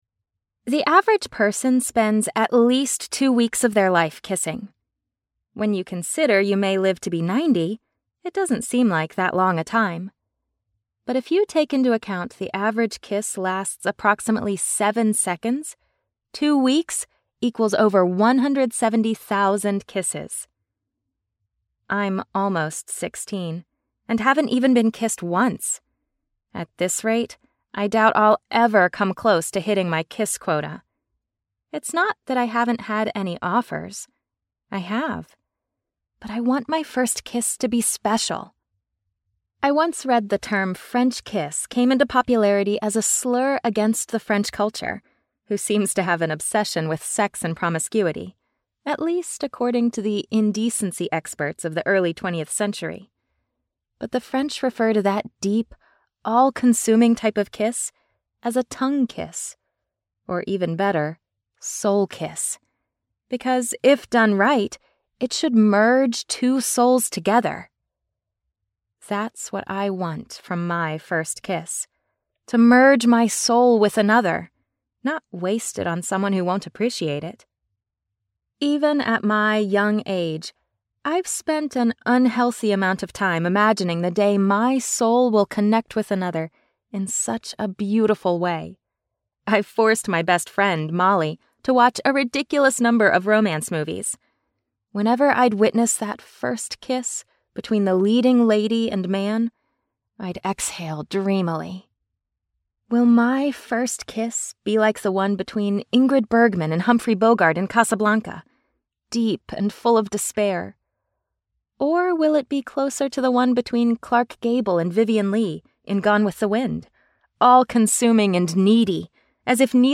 Promise audiobook